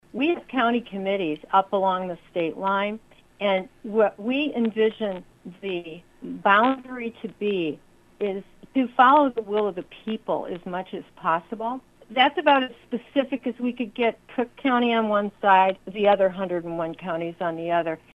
new-il-interview-part-2.mp3